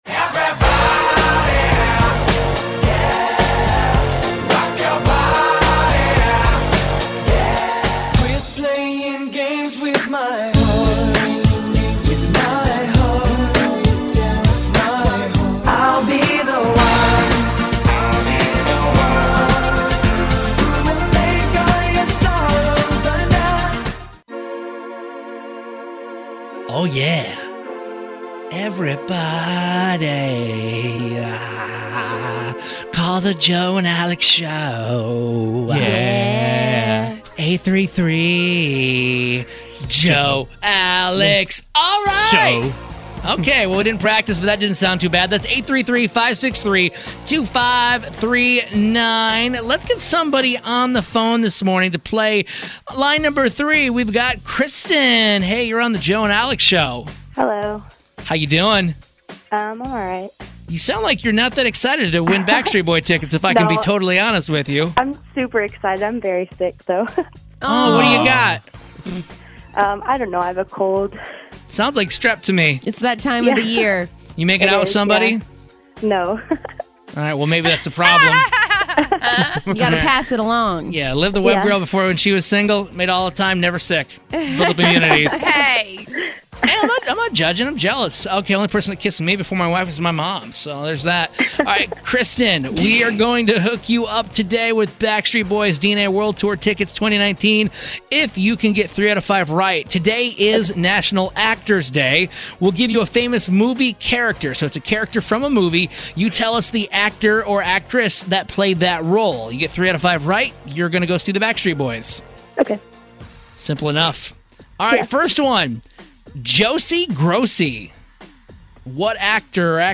We name a famous movie character and a caller has to guess the correct actor that played them in order for them to win tickets to go see the Backstreet Boys. It ended in impressions and they were..not our best.